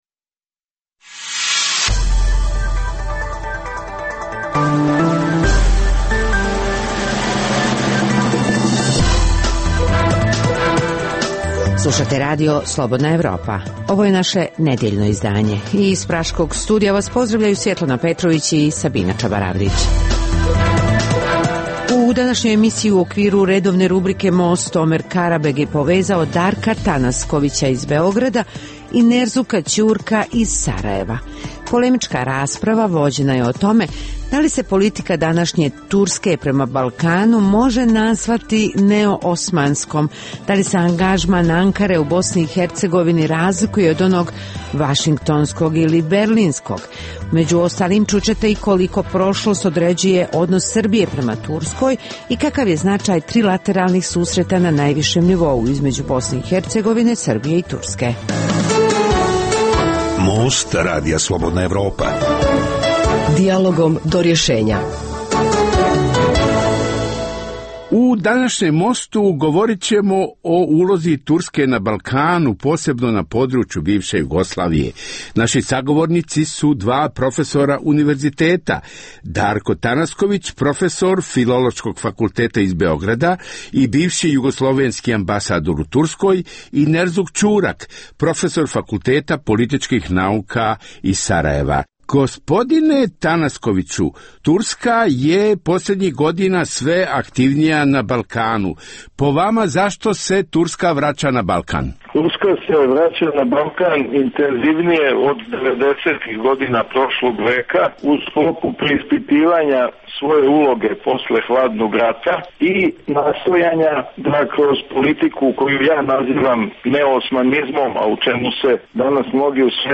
U najnovijem Mostu vođena je polemika o ulozi Turske na Balkanu.